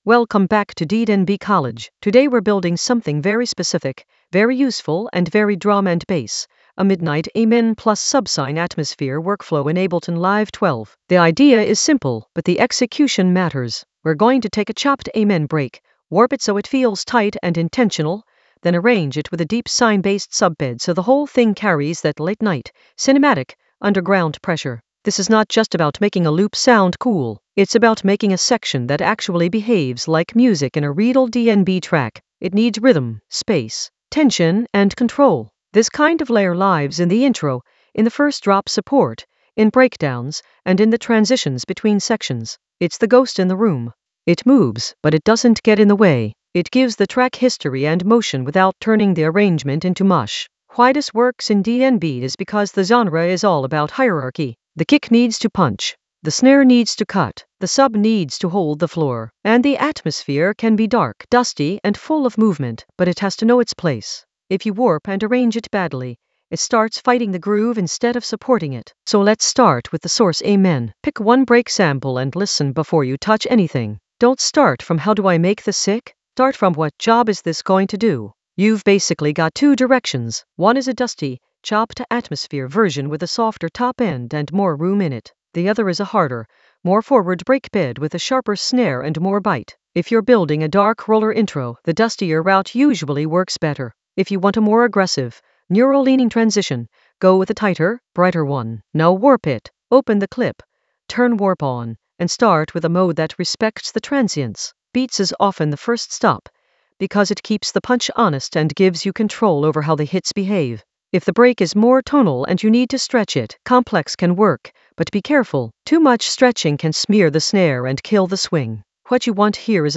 An AI-generated intermediate Ableton lesson focused on Midnight Amen a subsine workflow: warp and arrange in Ableton Live 12 in the Atmospheres area of drum and bass production.
Narrated lesson audio
The voice track includes the tutorial plus extra teacher commentary.